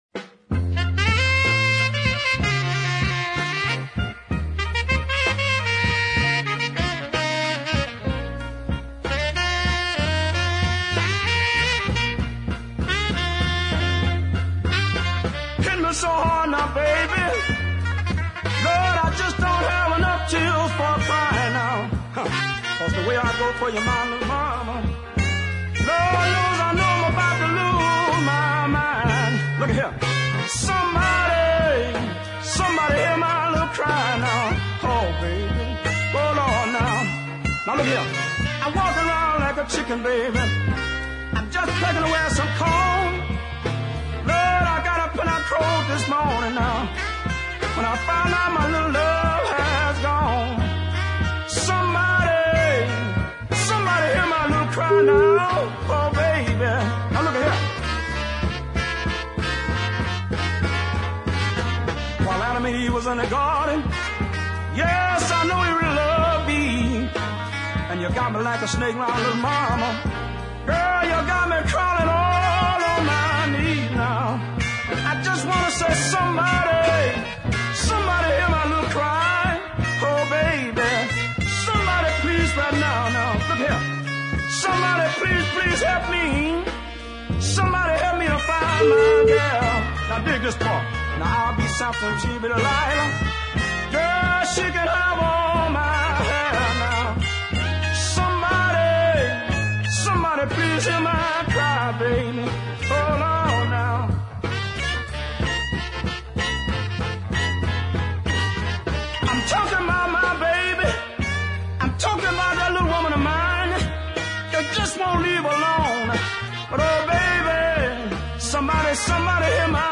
slow blues of considerable weight
he sounds even more desperate